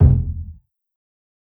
KICK_QUIZZY.wav